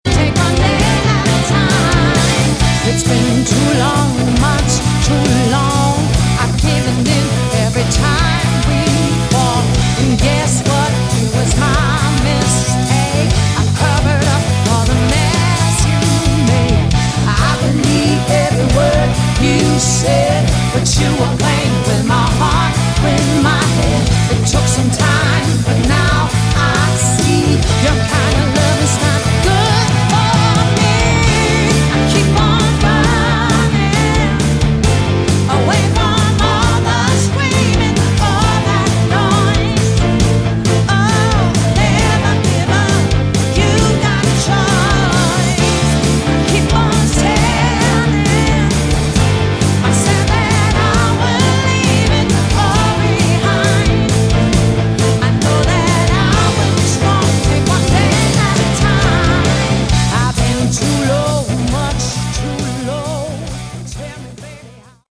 Category: AOR / Melodic Rock
guitars
drums
bass guitar
lead vocals
guest vocals